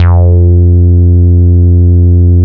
SMILE BASS 4.wav